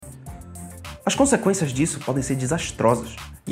El clásico sonido de internet que expresa rechazo o desaprobación con un 'naaaah' vocal cómico. Perfecto para reaccionar cuando algo sale mal o no te gusta una idea.
Etiquetas: meme, voice, viral